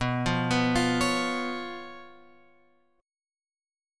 B Suspended 2nd Flat 5th